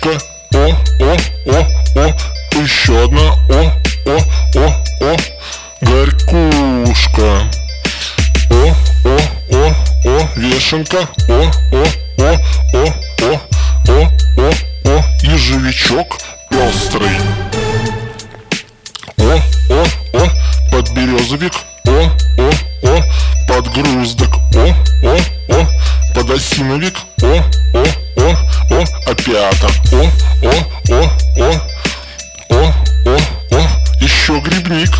Жанр: Рок / Танцевальные / Русские